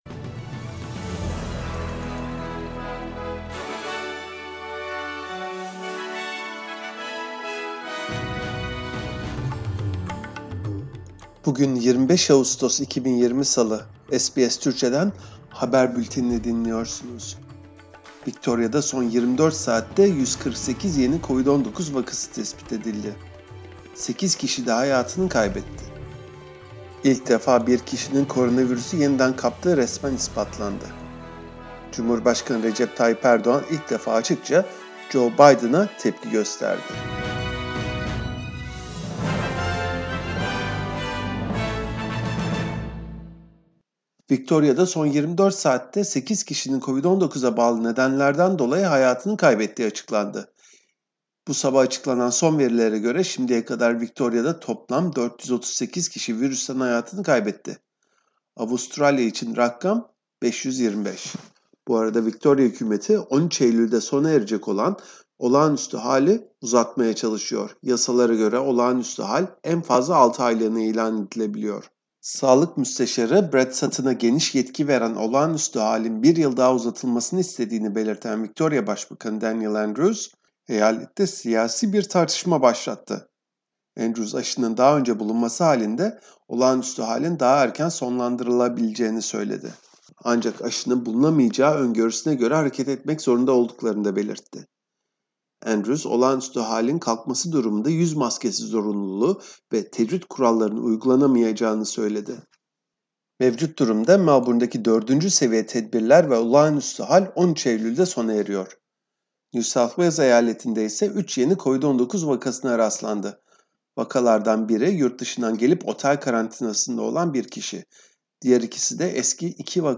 SBS Türkçe Haberler 25 Ağustos